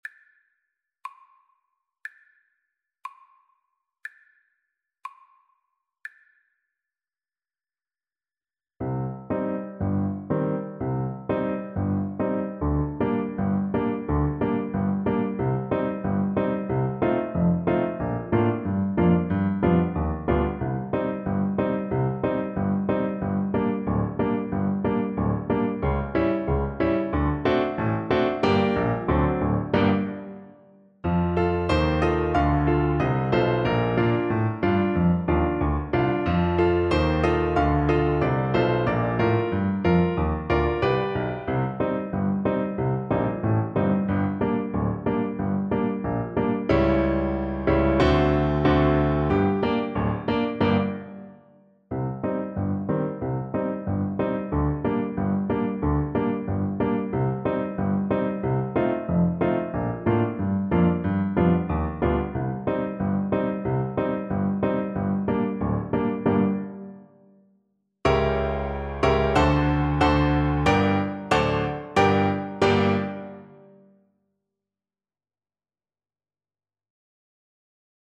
Trombone
3/4 (View more 3/4 Music)
Bb major (Sounding Pitch) (View more Bb major Music for Trombone )
Steadily (first time) =c.60
Gypsy music for trumpet trombone